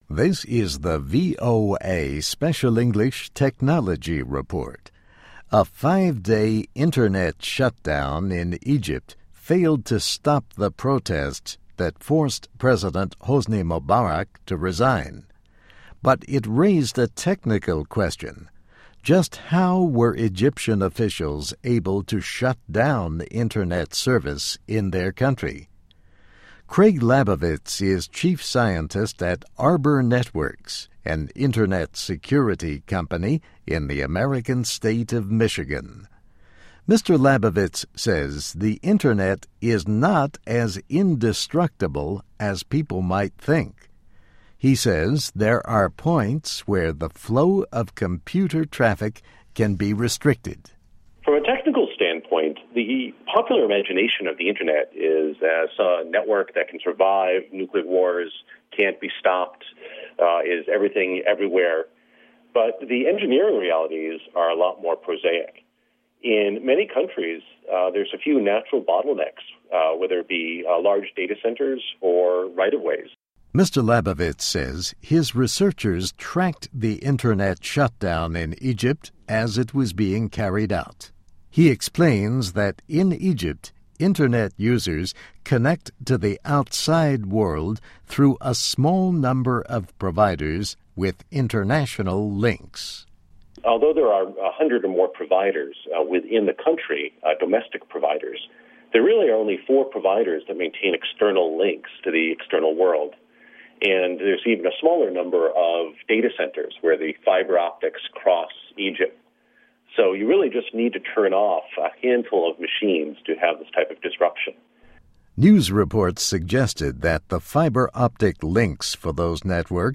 Technology Report